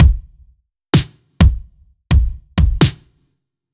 Index of /musicradar/french-house-chillout-samples/128bpm/Beats
FHC_BeatD_128-03_KickSnare.wav